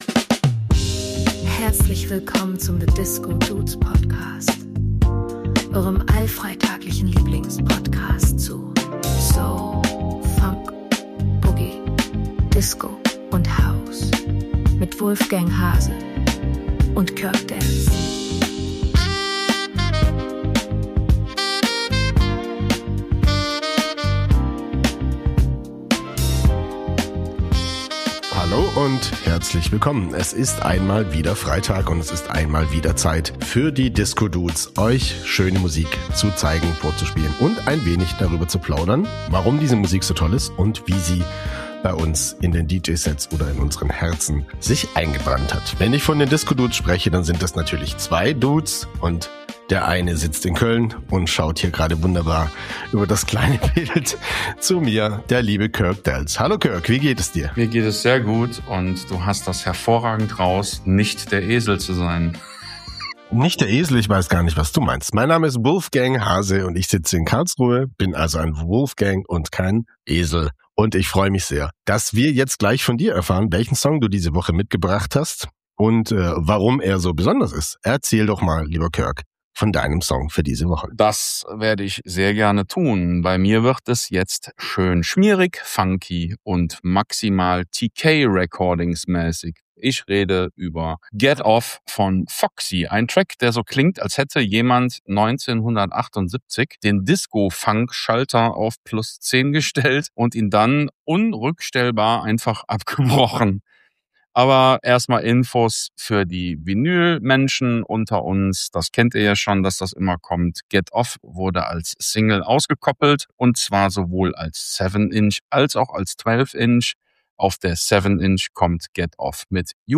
The Funk 🪩 goes Disco Dancing!